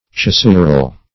cesural - definition of cesural - synonyms, pronunciation, spelling from Free Dictionary Search Result for " cesural" : The Collaborative International Dictionary of English v.0.48: Cesural \Ce*su"ral\, a. See C[ae]sural .
cesural.mp3